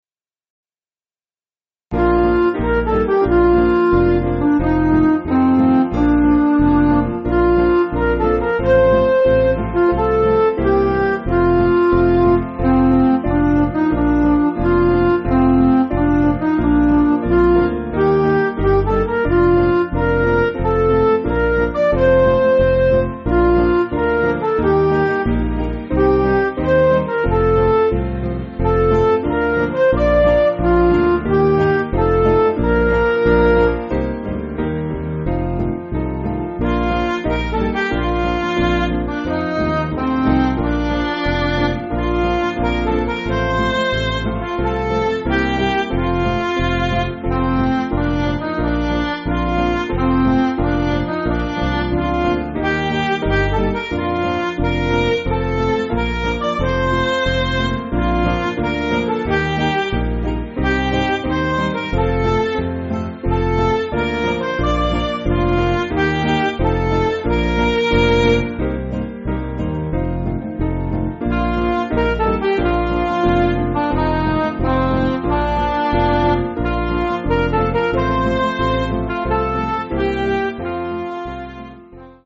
Piano & Instrumental
(CM)   4/Bb
in 4/4 time